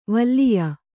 ♪ valliya